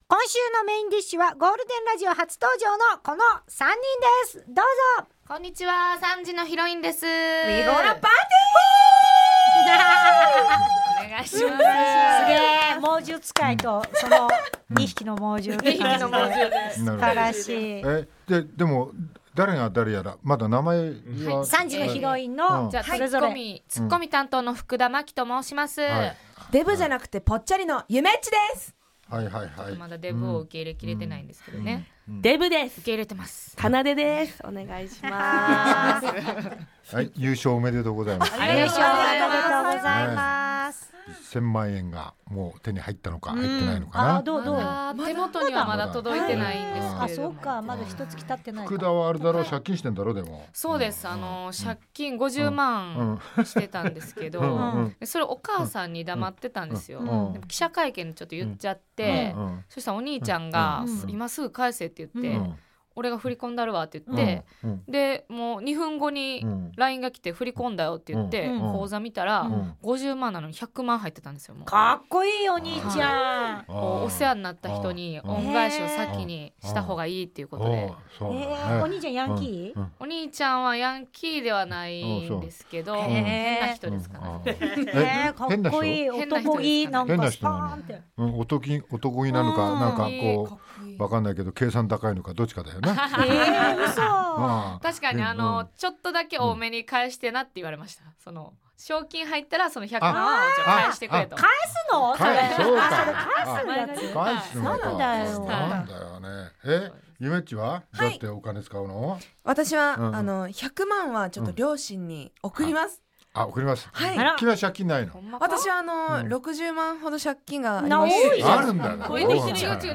1月9日のゲストコーナーには、3時のヒロインが登場しました。